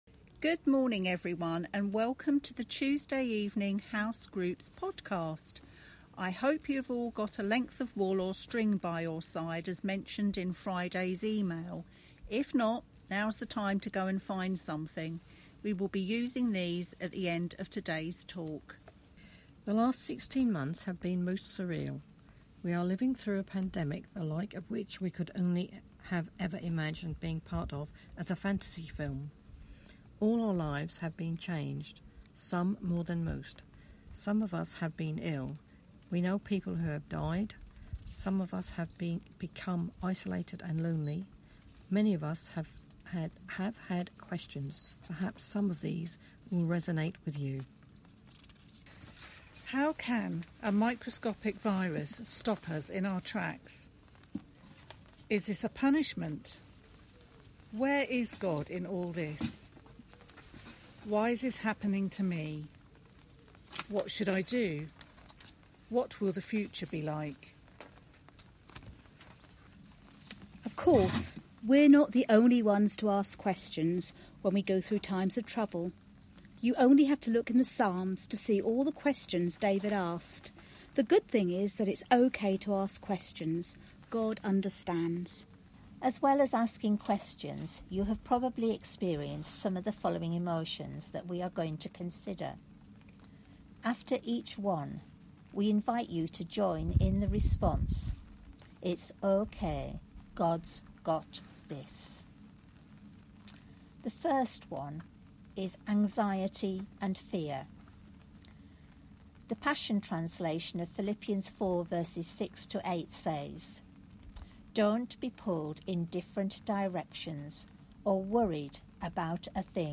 Preacher: Mautby Lifegroup | Series: Psalms | Sound Effect – Relaxing Forest
Service Type: Sunday Morning